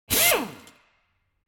جلوه های صوتی
دانلود صدای ماشین 4 از ساعد نیوز با لینک مستقیم و کیفیت بالا